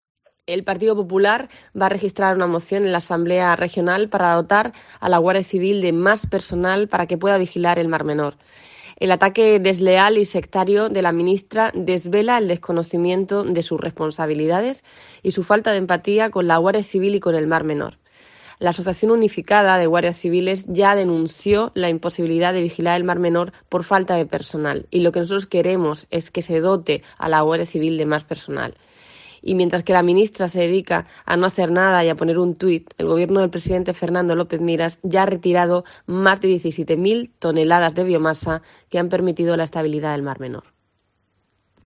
Miriam Guardiola, portavoz del Partido Popular de la Región de Murcia